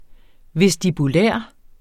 Udtale [ vεsdibuˈlεˀɐ̯ ]